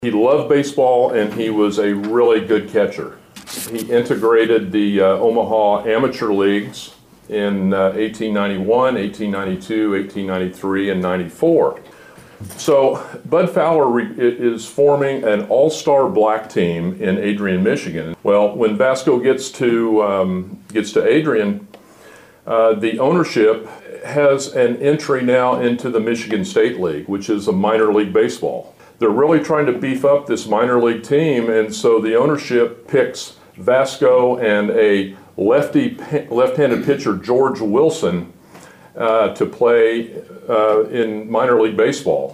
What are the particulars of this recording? A presentation to the City of Chillicothe was made as part of Black History Month.